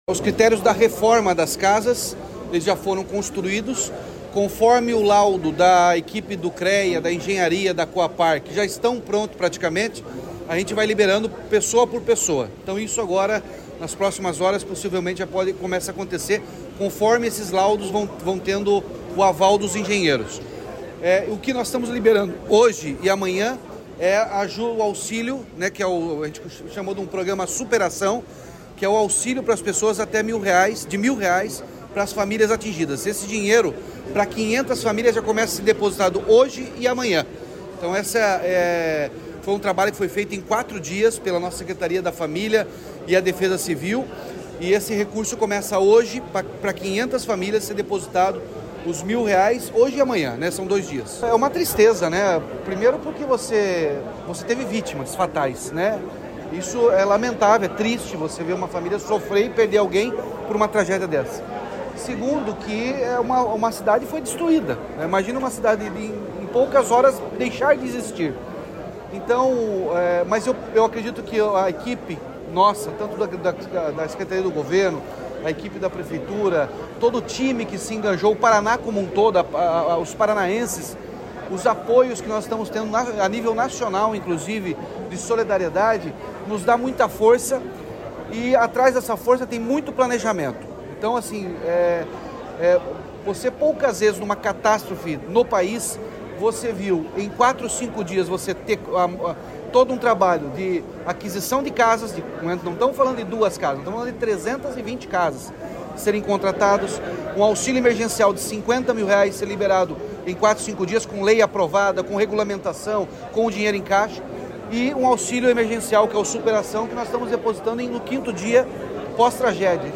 Sonora do governador Ratinho Junior sobre o início dos pagamentos do auxílio de R$ 1 mil para moradores de Rio Bonito do Iguaçu já nesta quinta